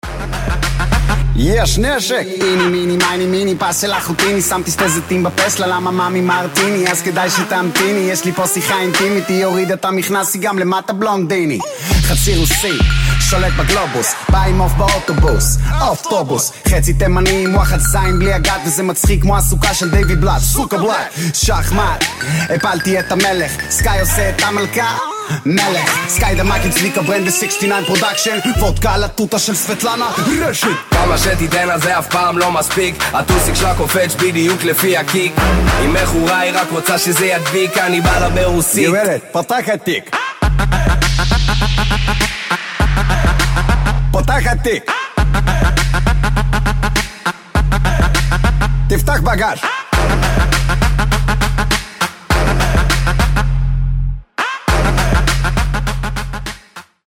мужской голос
Хип-хоп
забавные
dance
club
качающие